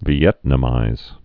(vē-ĕtnə-mīz, vyĕt-, vēĭt-)